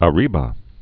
(ə-rēbə)